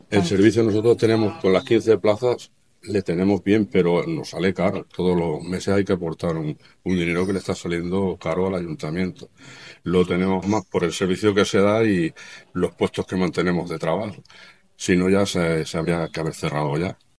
Alcalde Valdecañas de Tajo
Valdecanas-de-Tajo_Alcalde.mp3